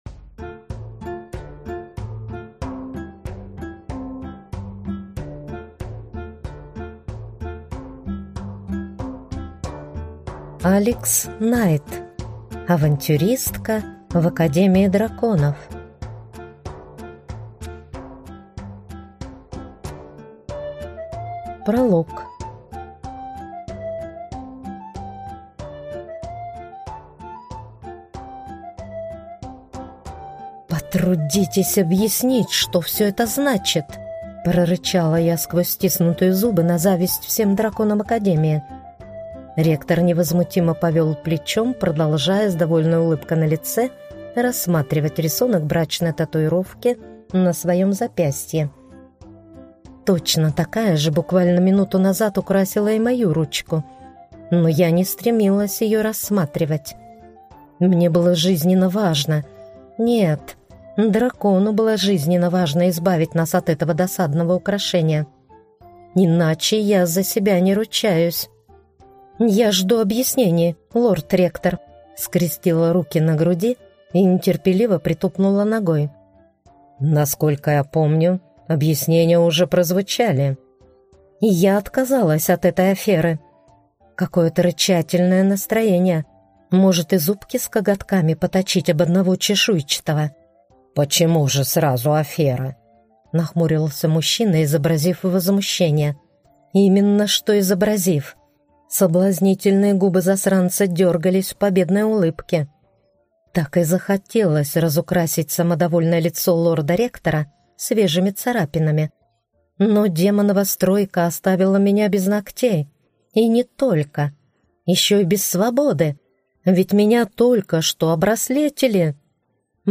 Аудиокнига Авантюристка в Академии Драконов | Библиотека аудиокниг